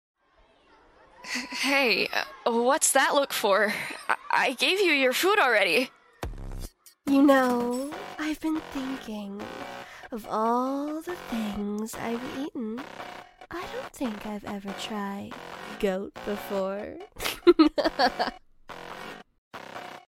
Comic Dub